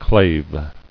[clave]